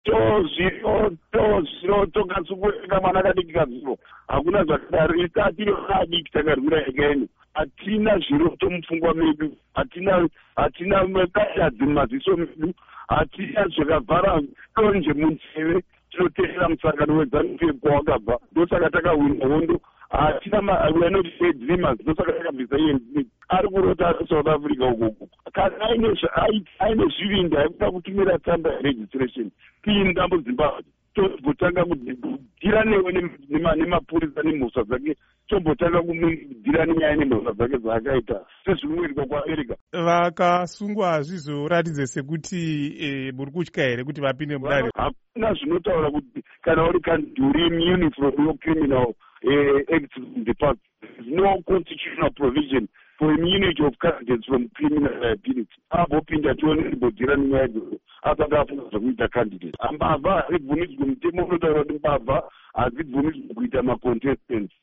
Hurukuro naVaChris Mutsvangwa